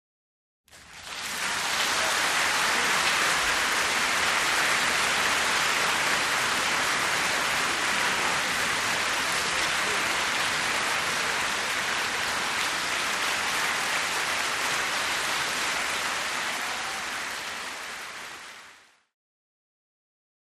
Concert Hall Theater
Applause 2 - Large Crowd - Shorter Interior Music Hall Orchestra Some bravo